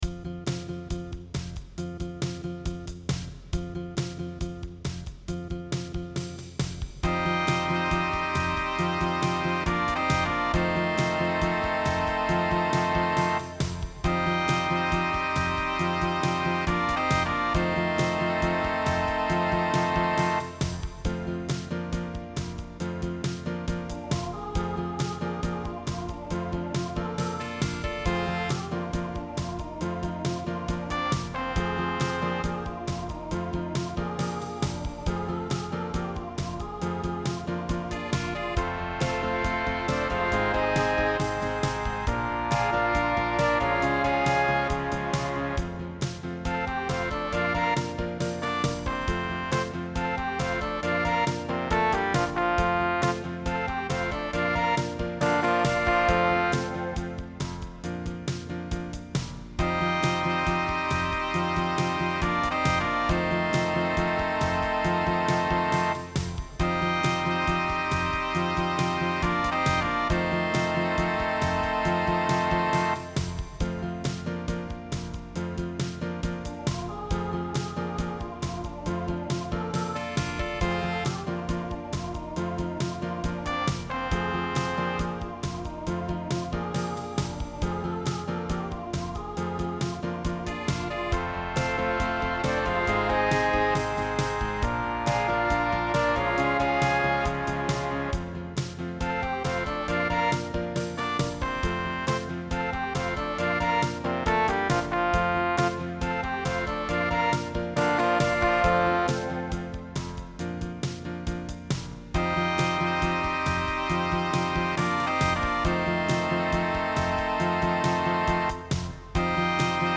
ORCHESTRA DIDATTICA